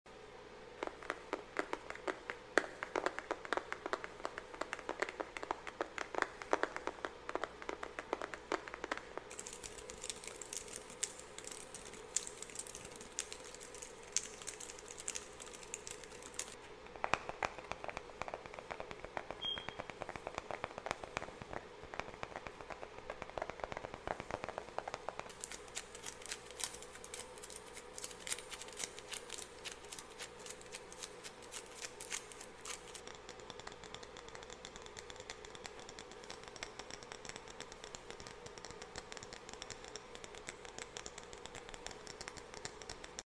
Low Quality But High Tingles😏 Sound Effects Free Download